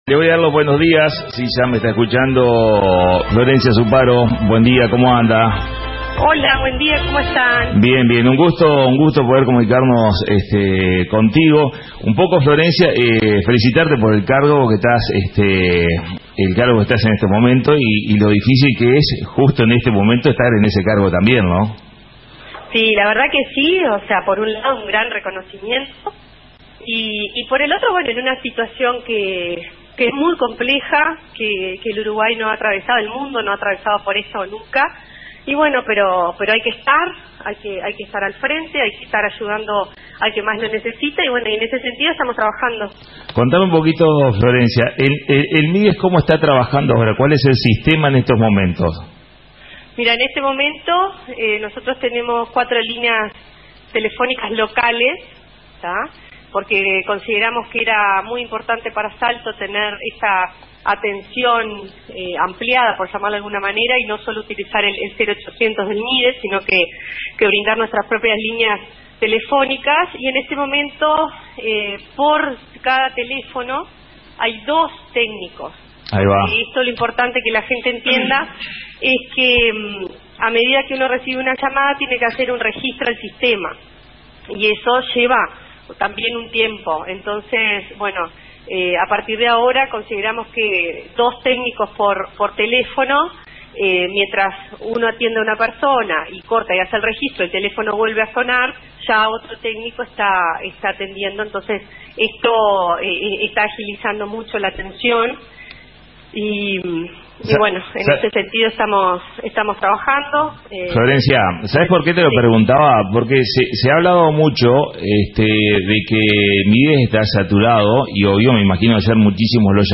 Hablamos con la Directora interina del Mides Florencia Supparo Sierra.